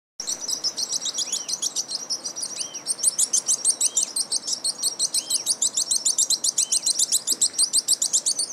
Nome científico: Aphrastura spinicauda
Nome em Inglês: Thorn-tailed Rayadito
Localidade ou área protegida: Parque Nacional Lanín
Condição: Selvagem
Certeza: Gravado Vocal
Rayadito.mp3